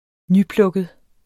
Udtale [ ˈnyˌplɔgəð ]